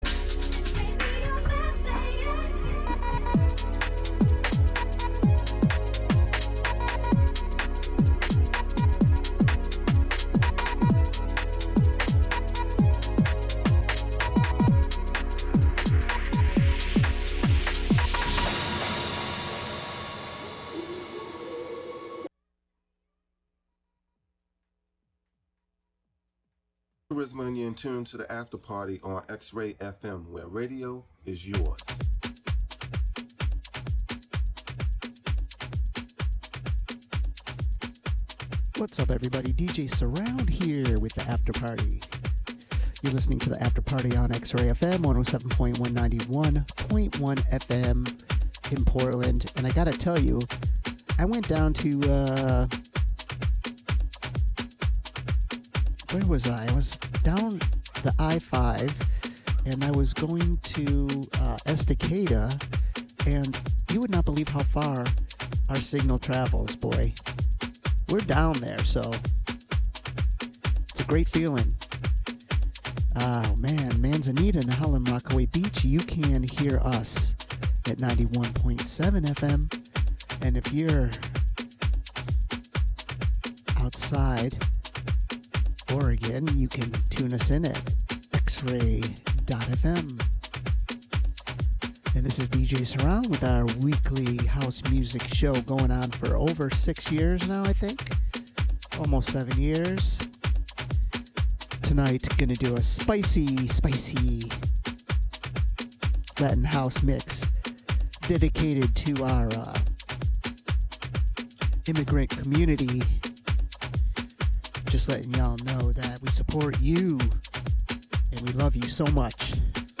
underground dance cuts